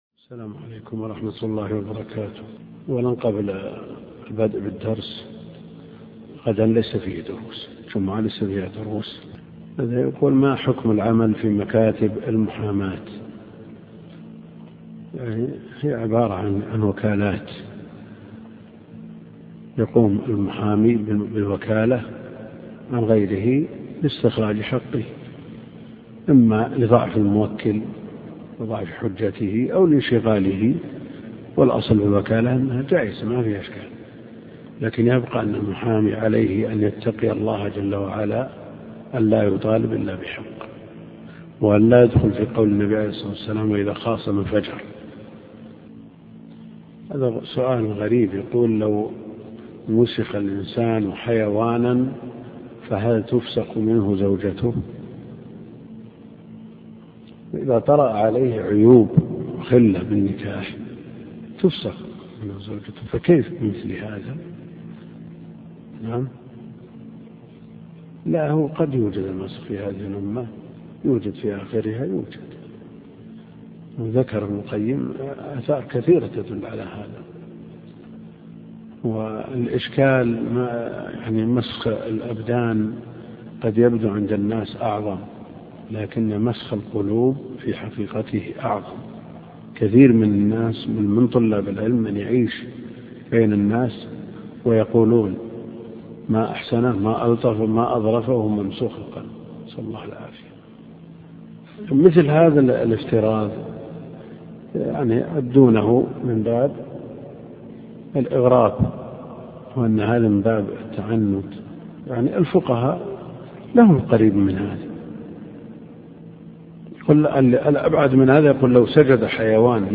الدرس (16) شرح سنن ابن ماجه - الدكتور عبد الكريم الخضير